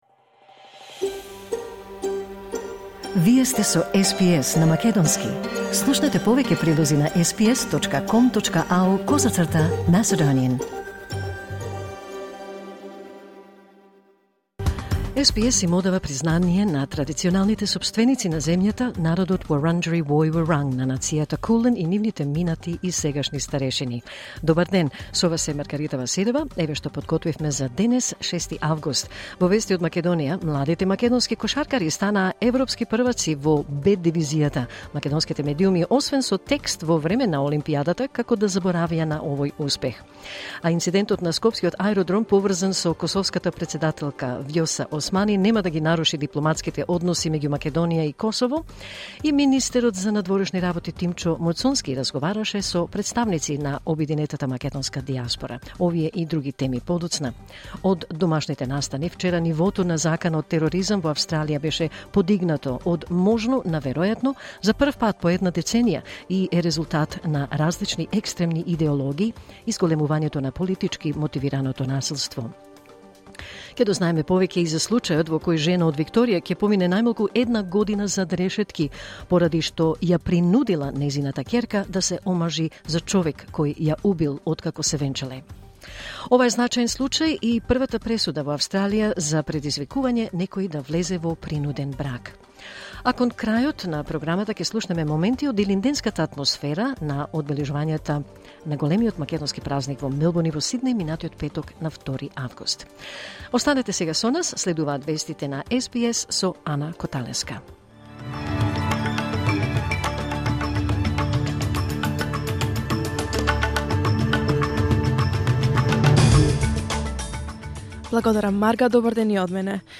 Програмата на СБС на македонски емитувана во живо на 6 август 2024